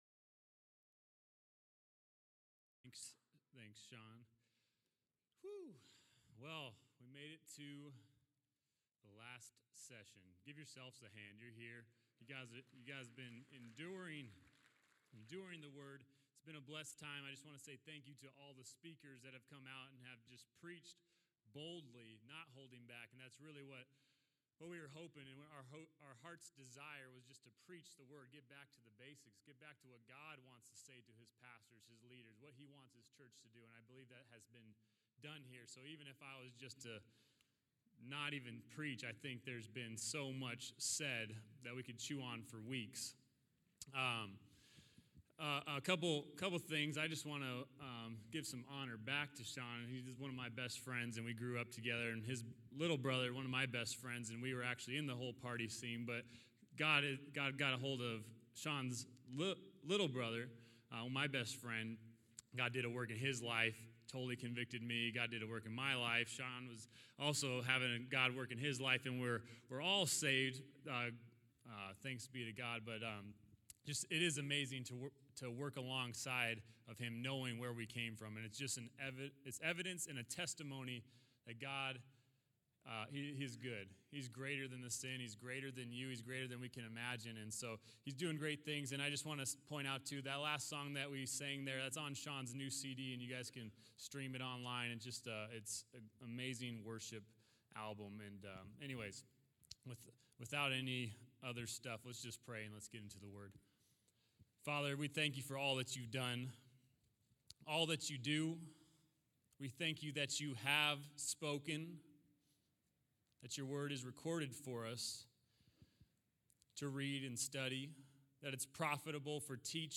Southwest Pastors and Leaders Conference 2019